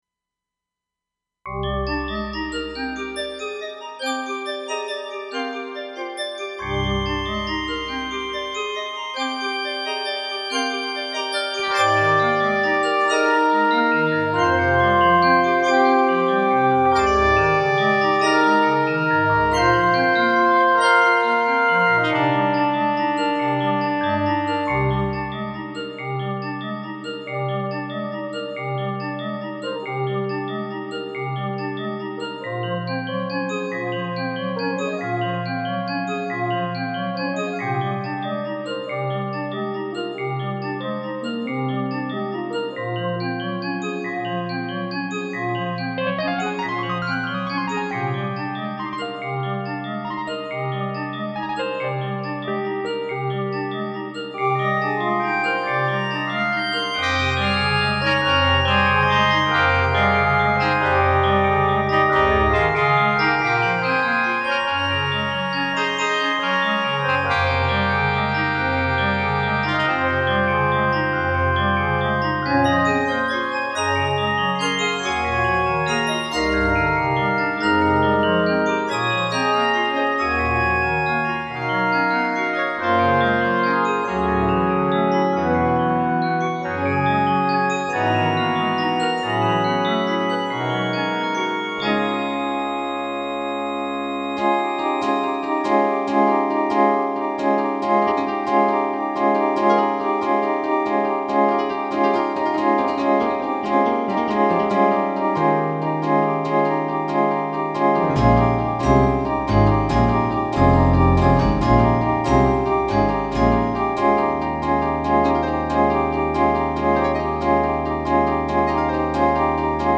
MSC-04S;  MPC-04S Orchestra